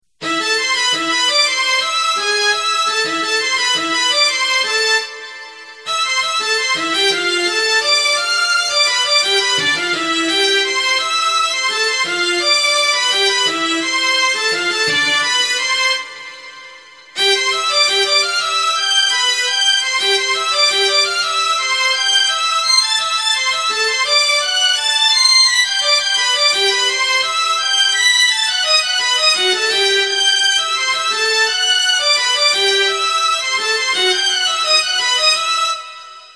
Melody only - Played on the violin, this is the primary line of the song.
har_violin.mp3